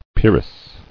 [peer·ess]